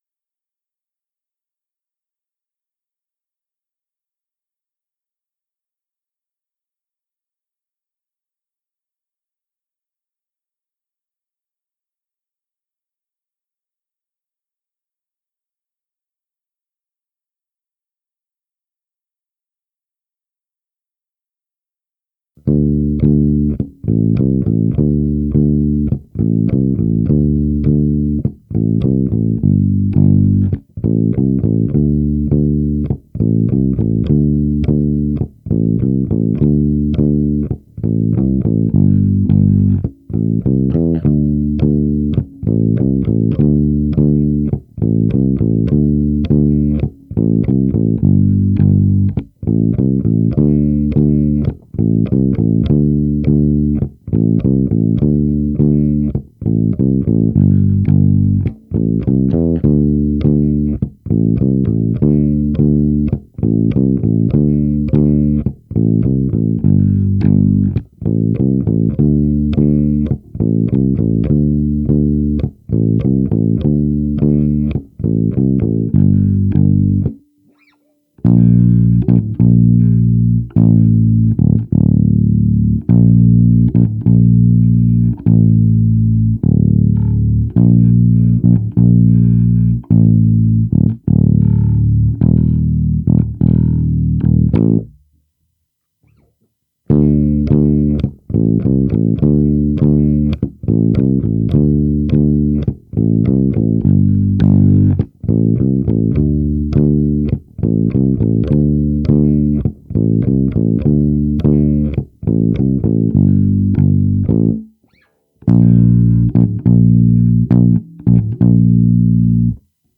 bass only